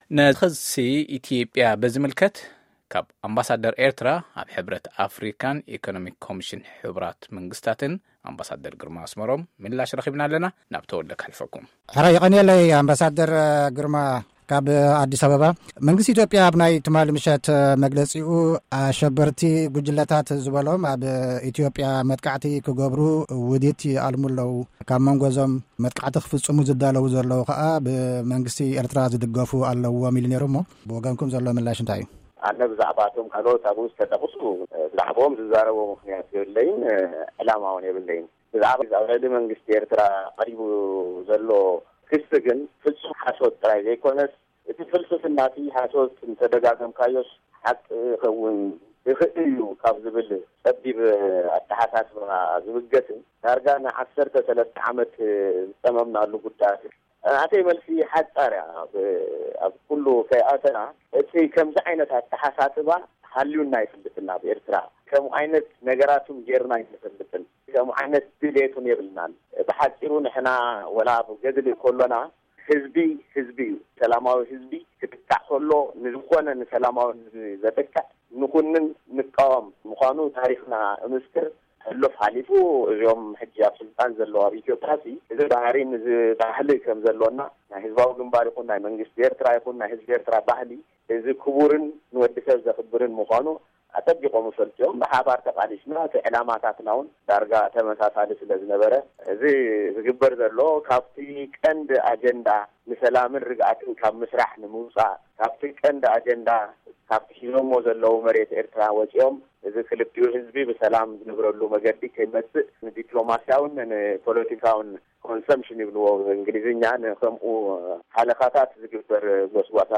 ኣብ ሕብረት ኣፍሪቃን ኤኮኖሚክ ኮሚሽን ሕቡራት ሃገርትን ኣምባሳደር ኤርትራ፡ ግርማ ኣስመሮም፡ ኣብ`ቲ ንቪኦኤ ዝሃቦ ቃለ-መጠይቕ፡ ሰበ-ስልጣን መንግስቲ ኢትዮጵያ፡ ፖለቲካዊ ረብሓ ክደልዩ ኸለዉ፡ ንኤርትራ ምጥቃን ንልዕሊ 10 ዓመት ዝረብረብናሉ`ዩ ኢሉ።